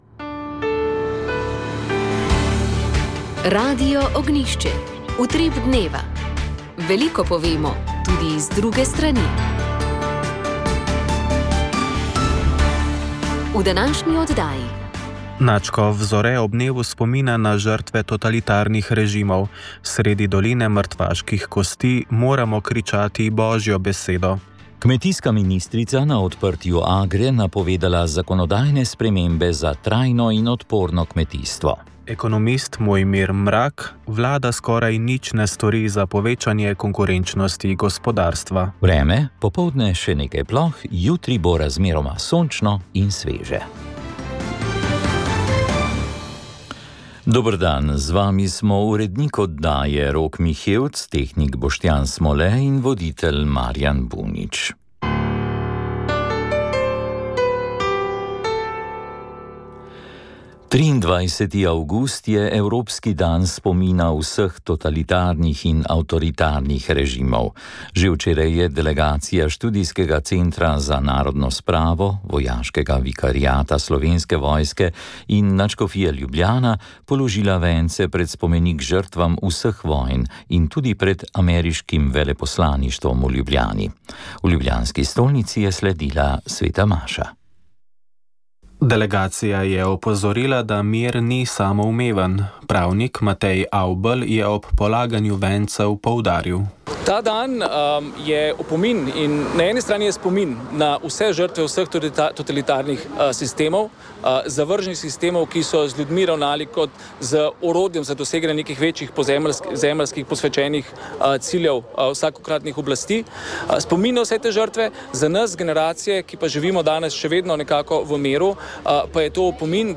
Z braslovškim županom Tomažem Žoharjem leto dni po ujmi, ki je prizadela velik del Slovenije: Obnova poteka prepočasi, kar posebej občutijo tisti, ki so ostali brez domov.